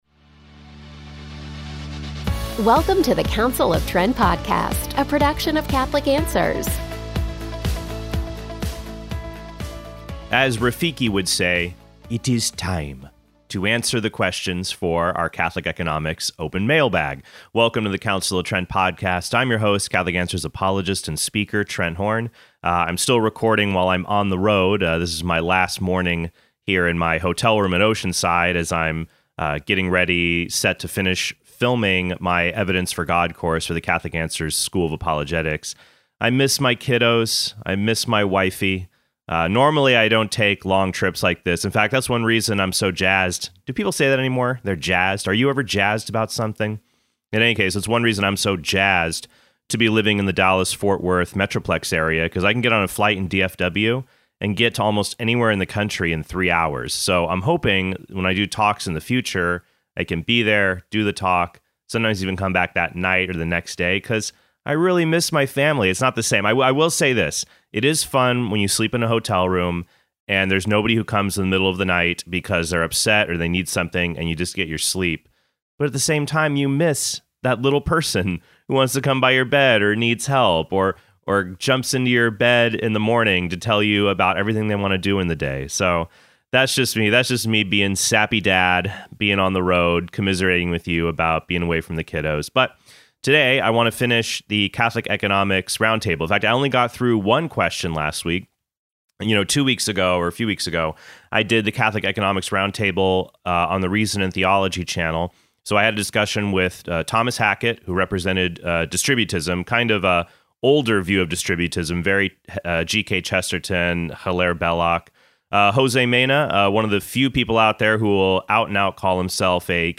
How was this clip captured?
I’m still recording while I’m on the road.